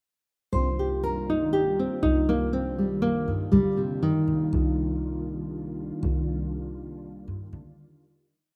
Next, we have a descending arpeggio pattern which skips one note after every chord tone.
minor 7 arpeggio example 2